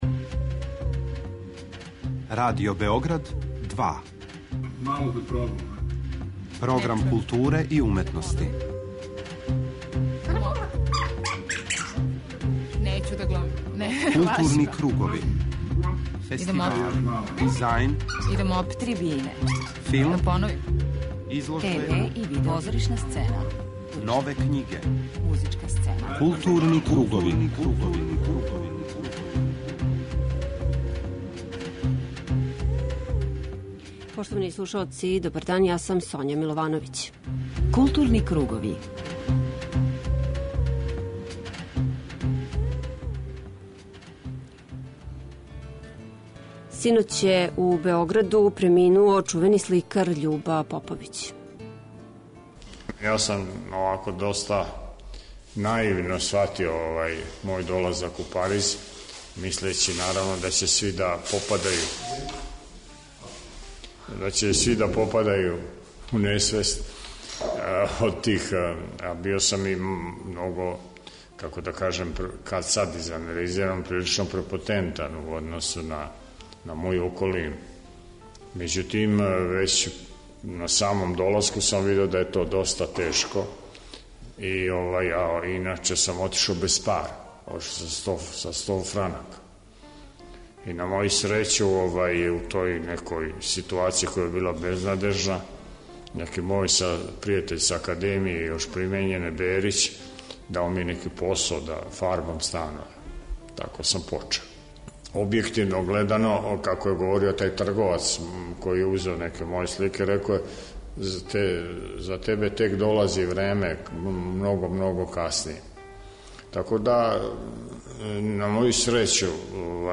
преузми : 41.14 MB Културни кругови Autor: Група аутора Централна културно-уметничка емисија Радио Београда 2.